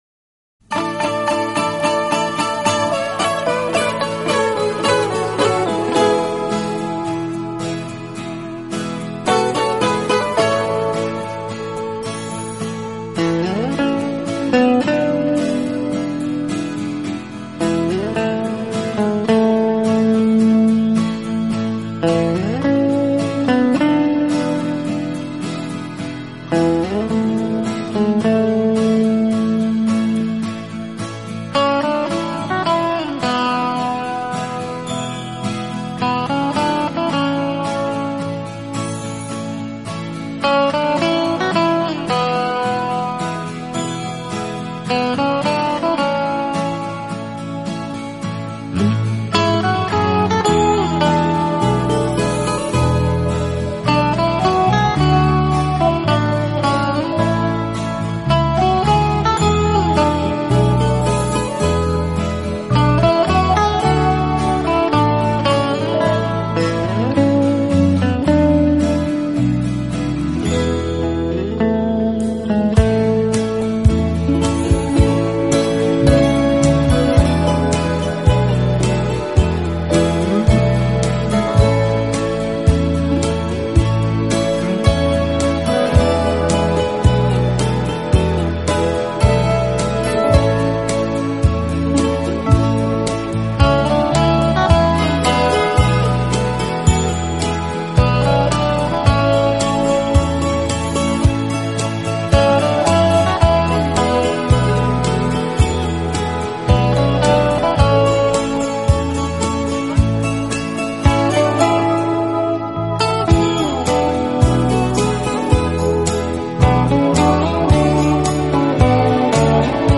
是乐队演奏的主要乐器，配以轻盈的打击乐，使浪漫气息更加浓厚。